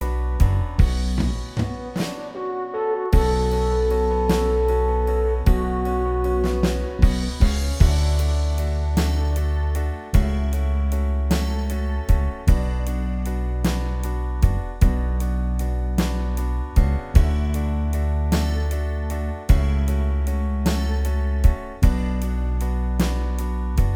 Minus Guitars Pop (1980s) 2:51 Buy £1.50